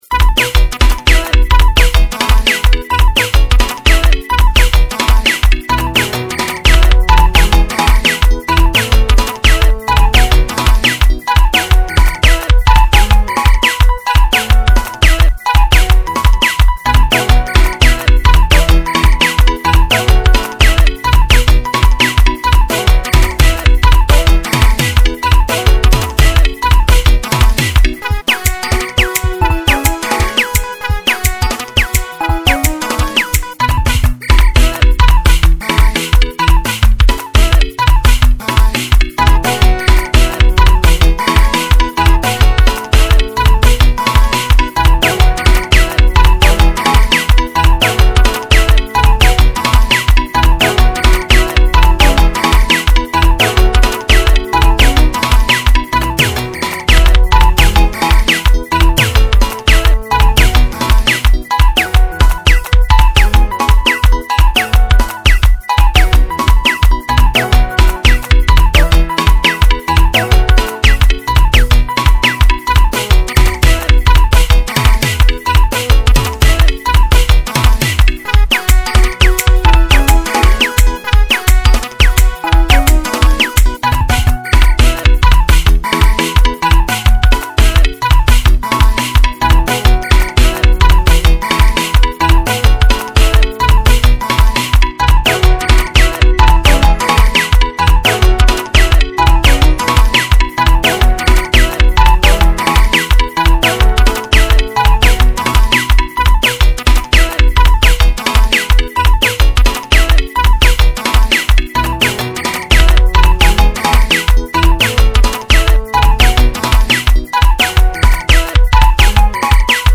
03:33 Genre : Xitsonga Size